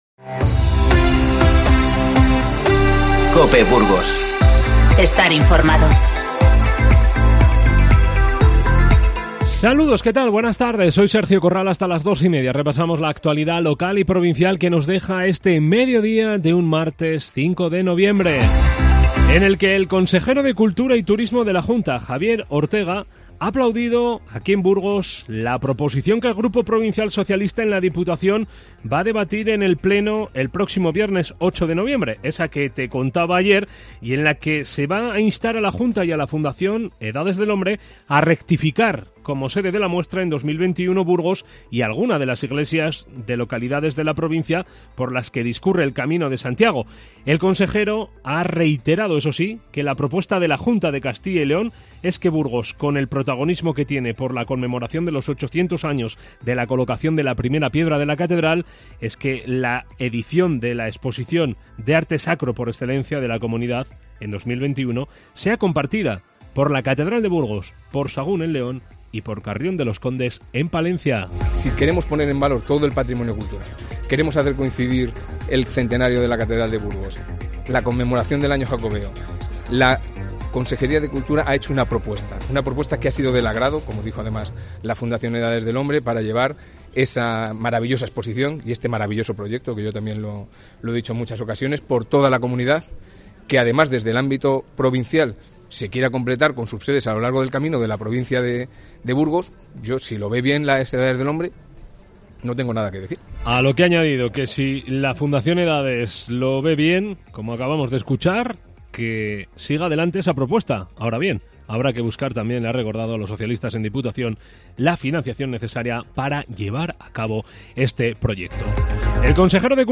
INFORMATIVO Mediodía 5-11-19